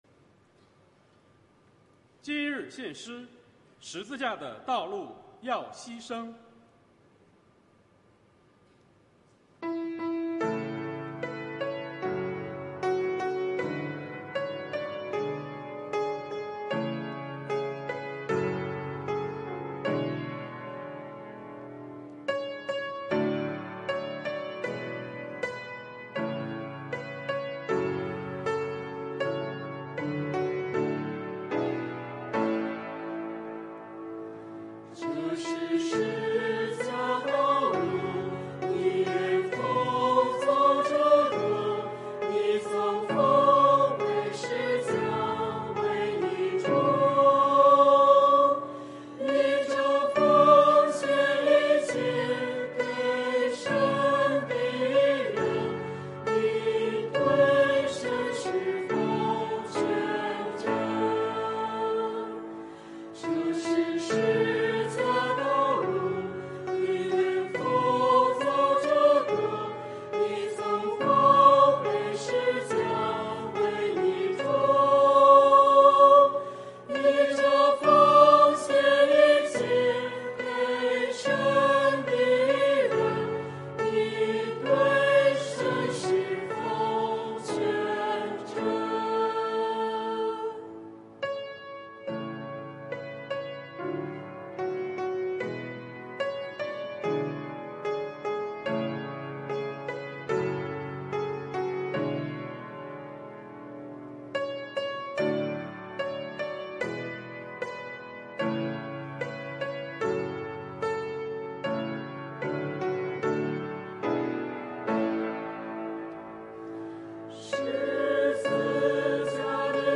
团契名称: 青年、迦密诗班
诗班献诗